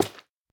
minecraft / sounds / dig / coral4.ogg
coral4.ogg